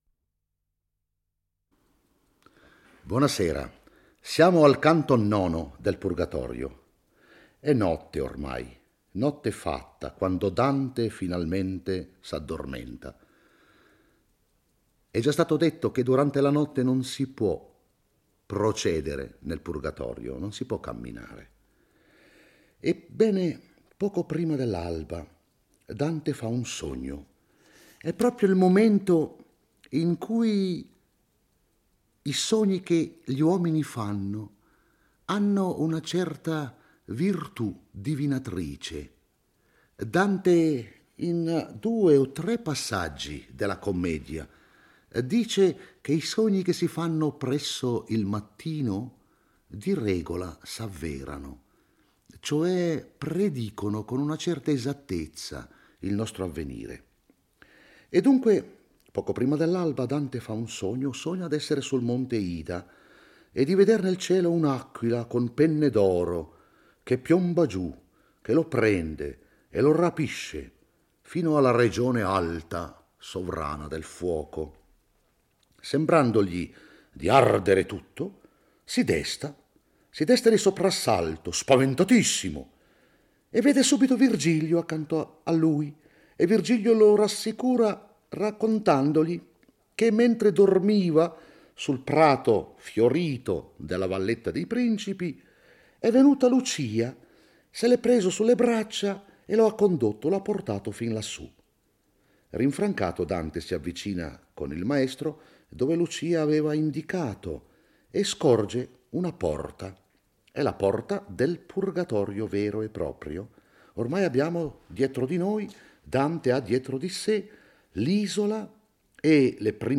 legge e commenta il IX canto del Purgatorio. Durante la notte Dante sogna di essere afferrato da un'aquila dalle piume d'oro e portato nella sfera del fuoco.